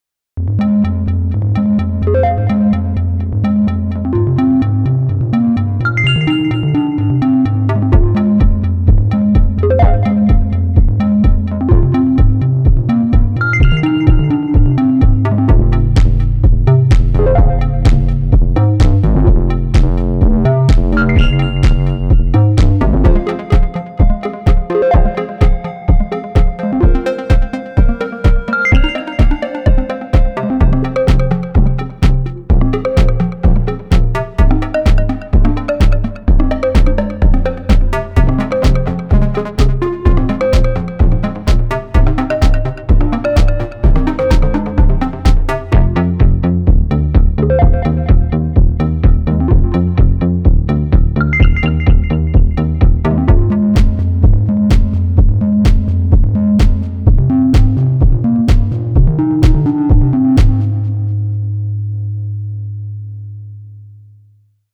Just having fun messing with melodic percussive parts.
Very musical, love it! This is a perfect video game soundtrack